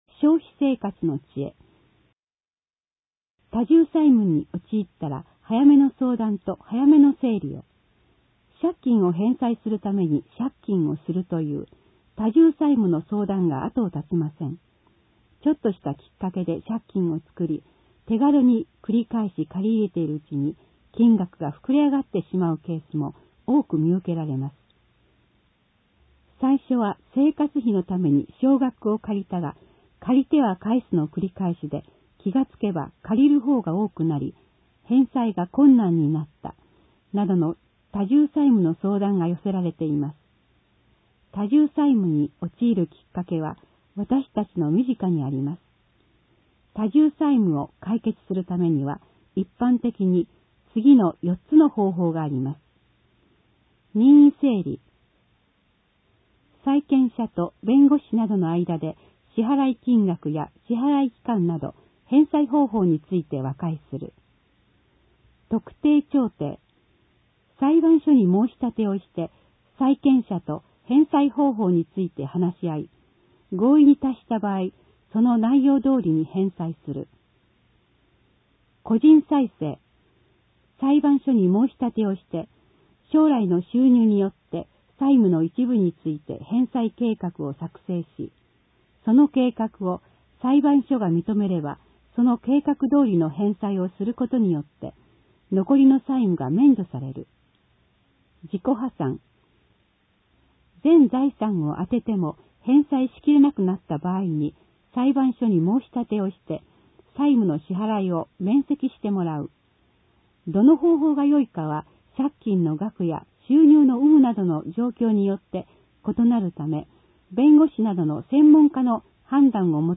音訳広報
広報しゅうなんを、音読で収録し、mp3形式に変換して配信します。 この試みは、小さな文字を読むことが辛い高齢者や軽度の視覚障害がある人に広報しゅうなんの情報を音声で伝えるために始めました。